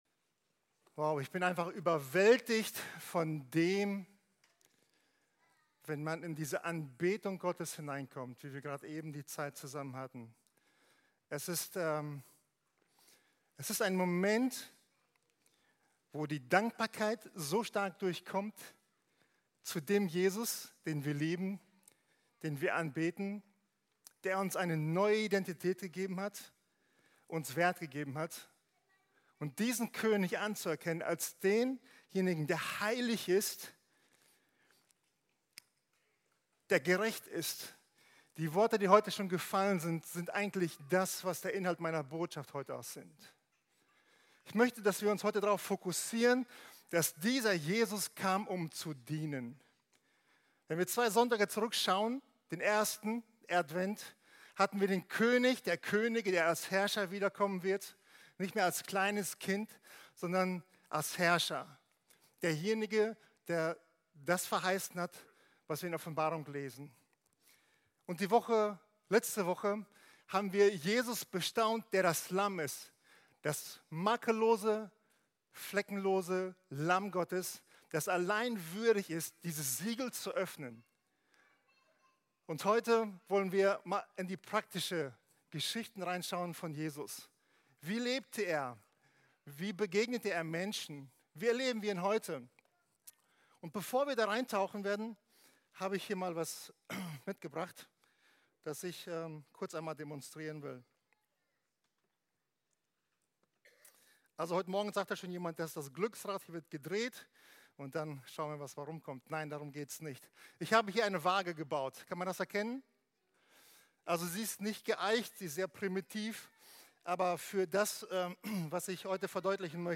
Podcast unserer Predigten